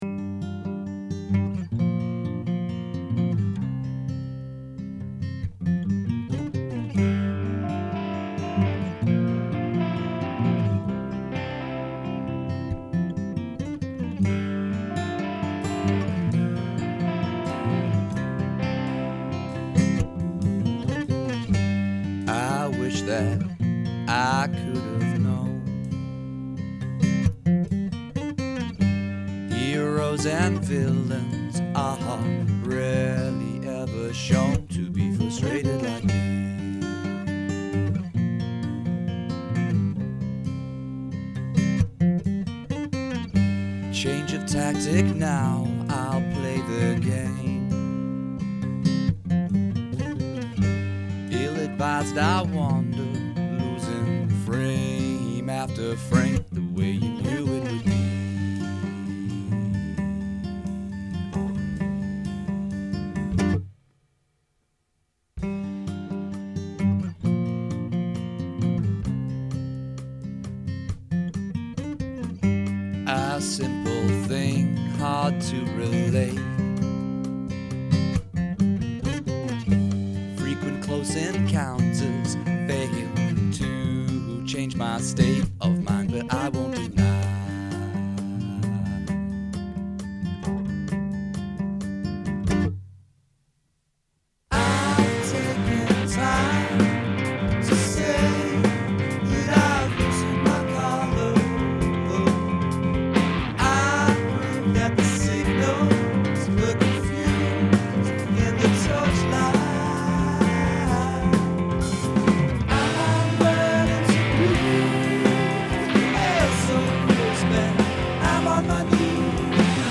I love the way this song changes and the driven bass-line that came from messing with my acoustic bass one evening.
This didn’t really work, except this sort of weird droning baseline and melancholic vocal were born. The verse jars a little, but awesome drumming and some nice licks